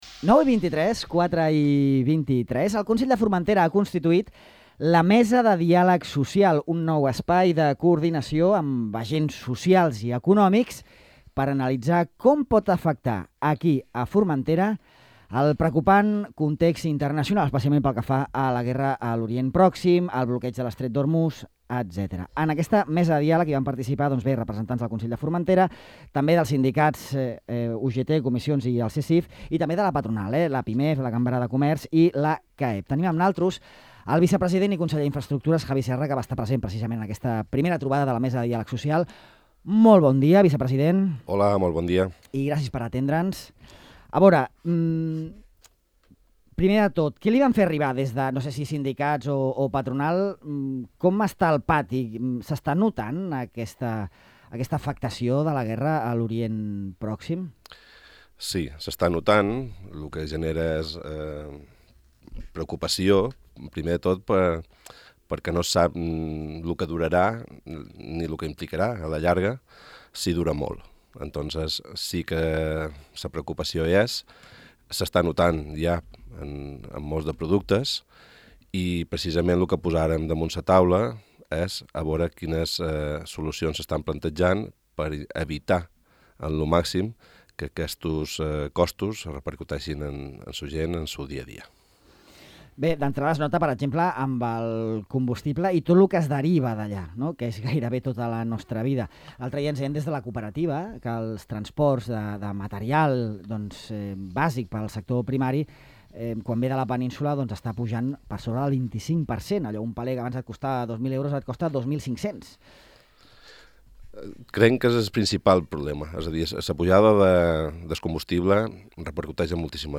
El vicepresident i conseller d'Infraestructures, Javi Serra, ha presentat a Ràdio Illa la nova Mesa de Diàleg Social del Consell de Formentera, que permetrà analitzar com afecta l'actual context internacional a l'economia de l'illa, especialment per la pujada del preu del petroli arran de la guerra